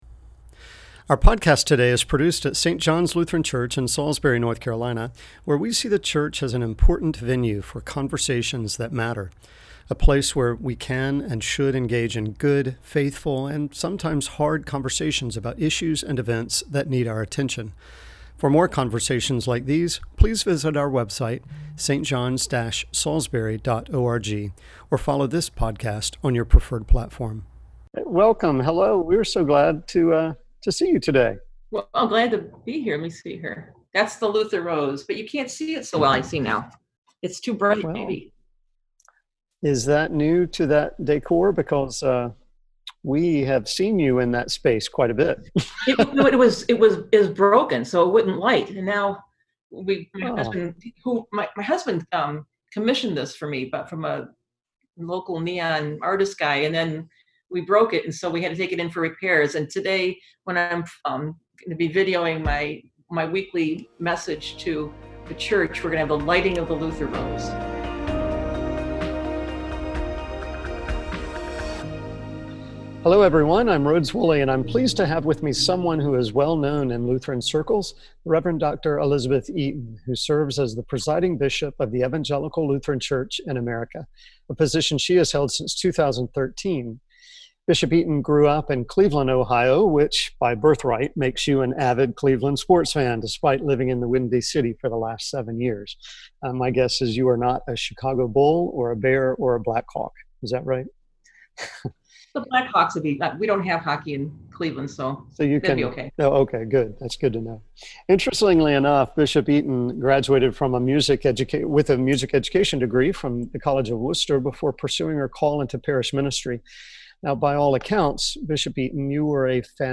Eaton is the first female presiding bishop of the ELCA, first elected in 2013.